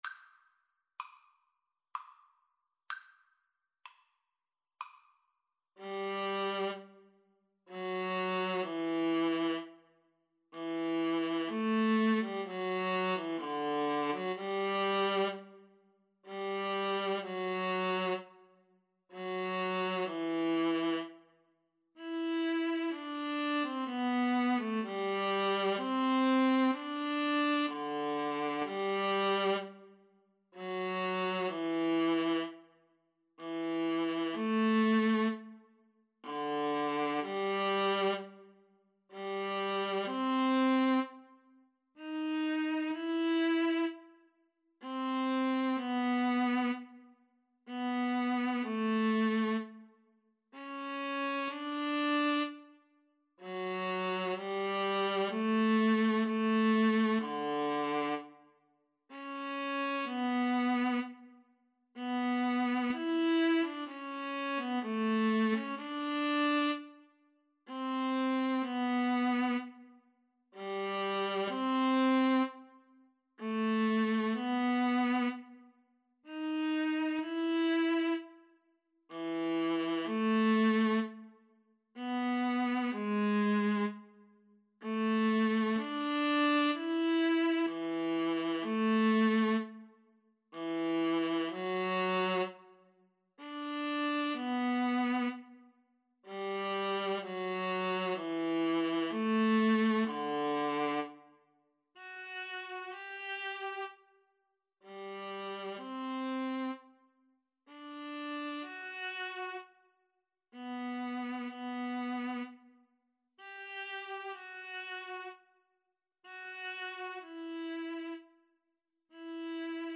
3/2 (View more 3/2 Music)
Classical (View more Classical Viola Duet Music)